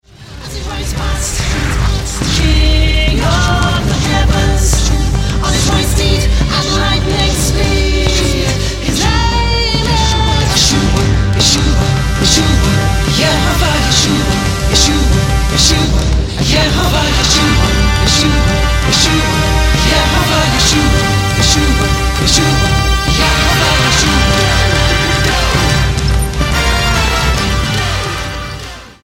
STYLE: Pop
Tremendous vocals and playing throughout